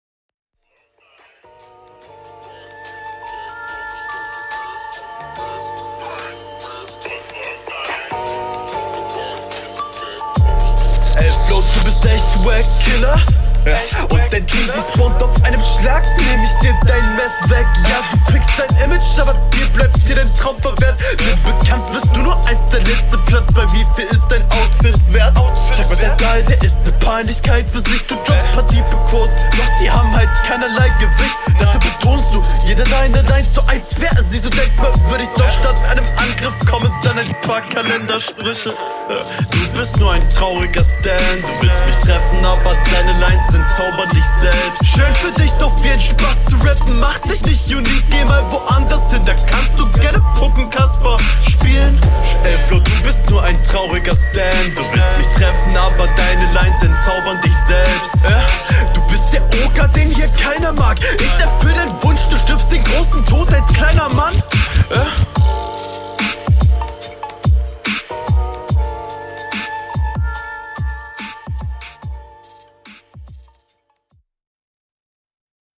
Soundtechnisch gefällt mir das echt gut.